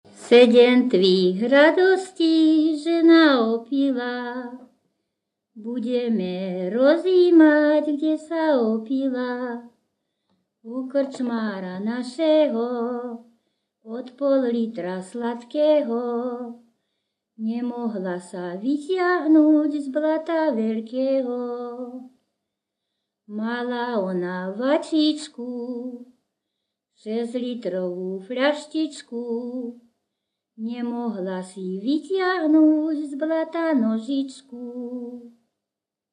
Popis sólo ženský spev bez hudobného sprievodu
Všeobecný popis Žartovná pijanská pieseň sa spievala pri rôznych obradových príležitostiach, napríklad počas hodovania na svadbe alebo na krstinách.
Miesto záznamu Litava
Predmetová klasifikácia 11.1. Piesne späté so životným obyčajovým cyklom